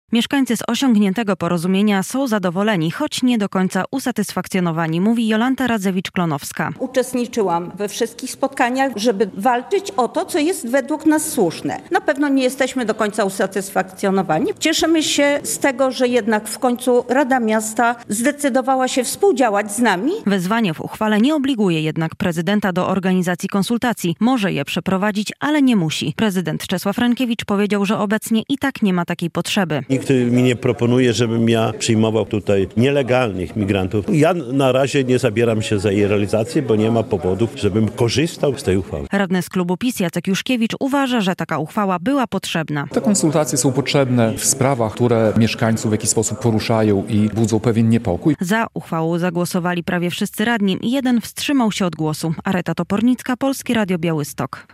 Suwalscy radni o mieszkaniach dla migrantów - relacja